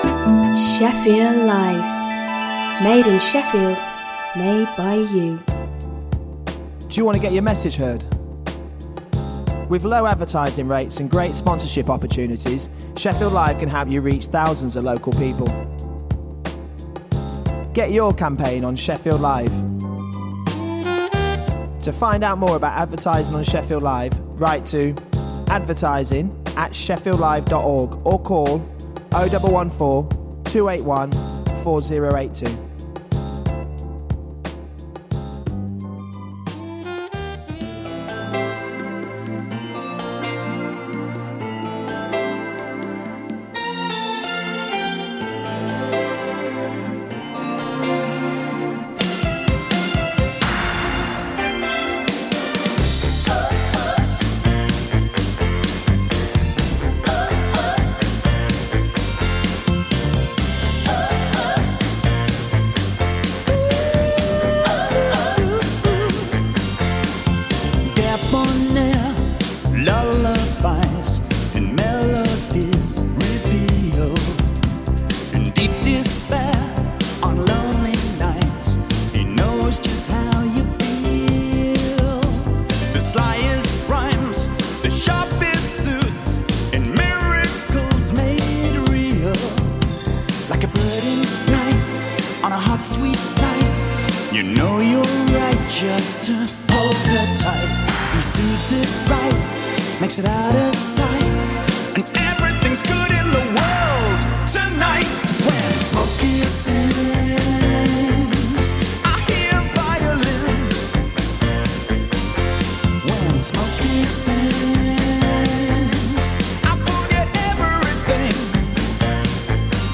Write Radio is a two hour radio show which showcases new and local writing from the people of South Yorkshire.